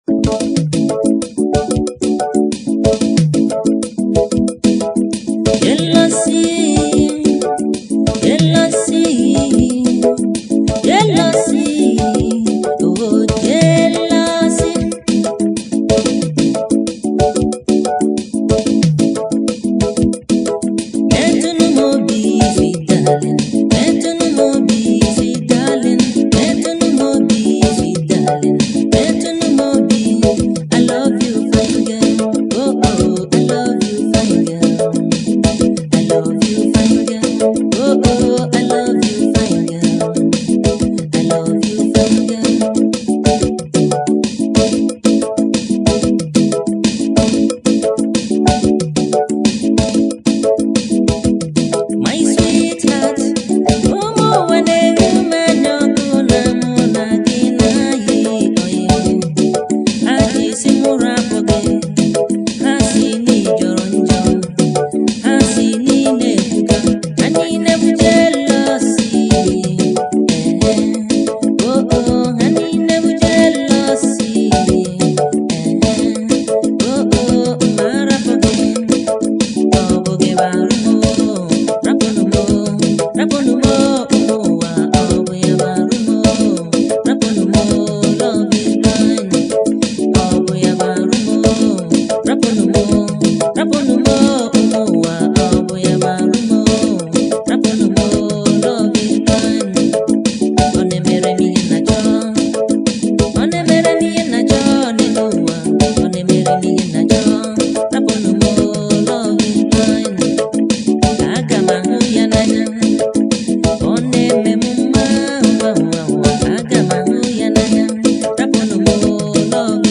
October 16, 2024 admin Highlife Music, Music 0